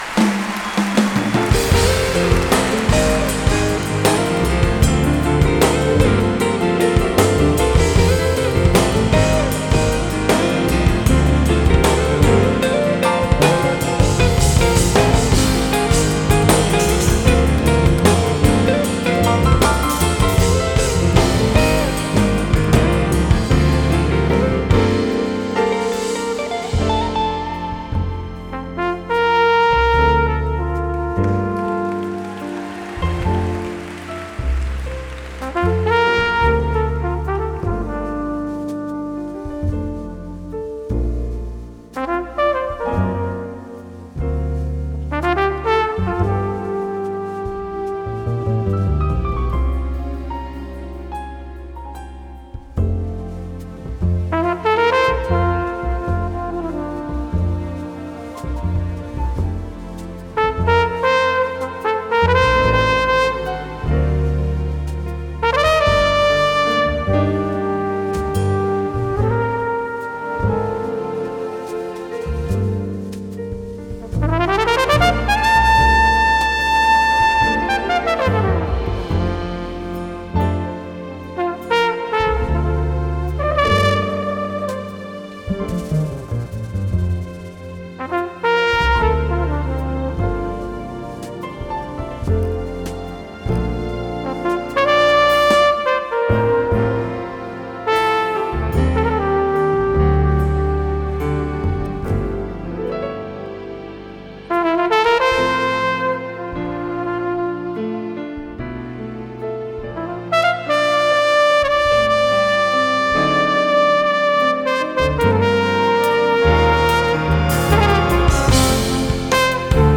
Genre: Jazz